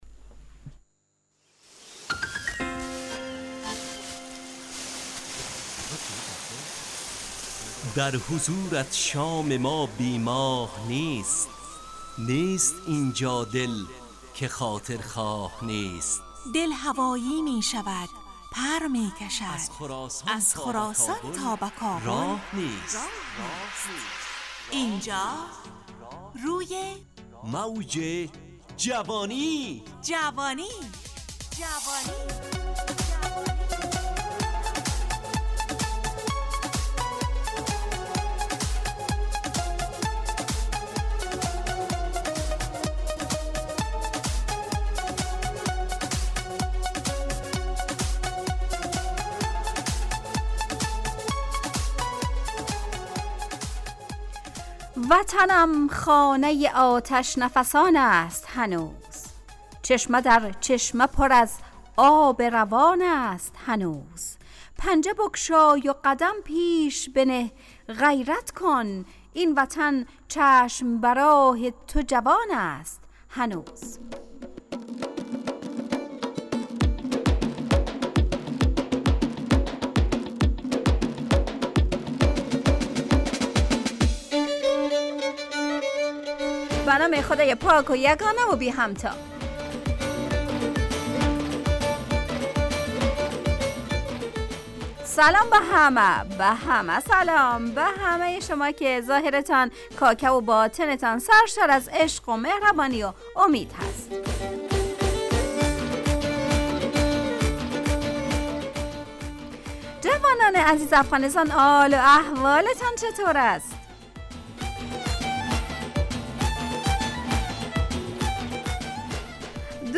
همراه با ترانه و موسیقی مدت برنامه 55 دقیقه . بحث محوری این هفته (ظاهر و باطن ) تهیه کننده